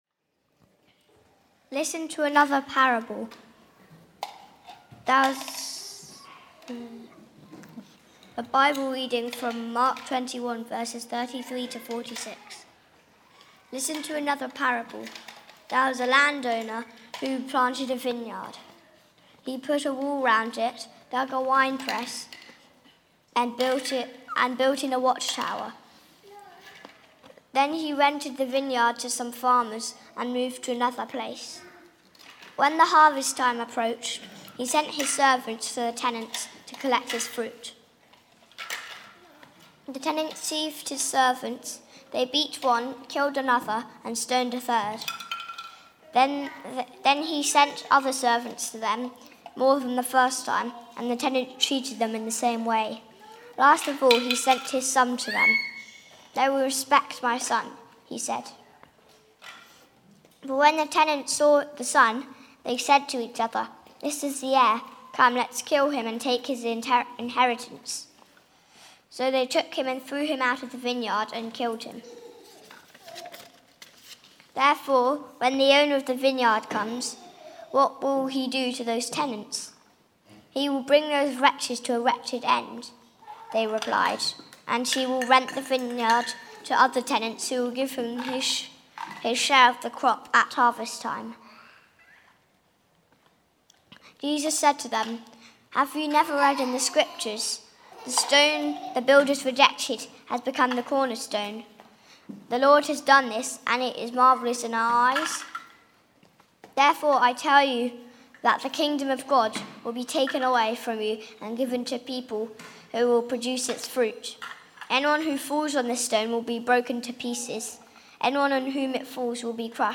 Coronation Sermon